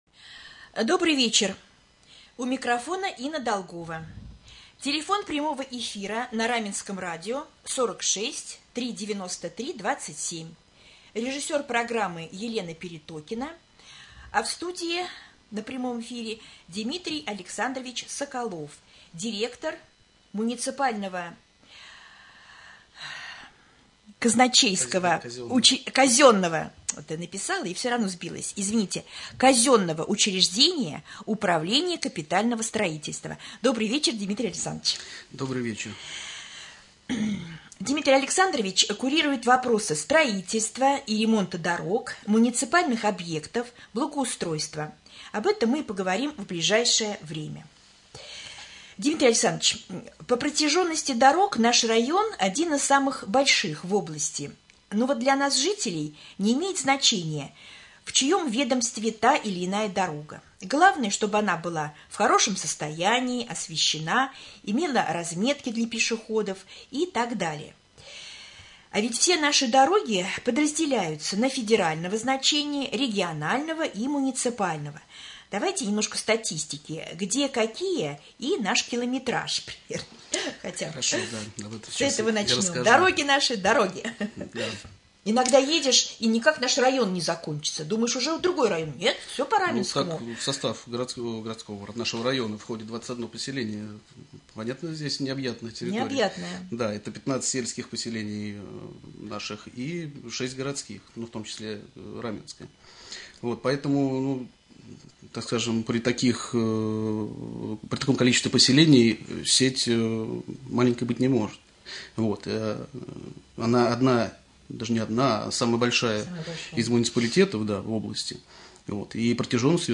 Прямой эфир.
2.-prjamoj-jefir.mp3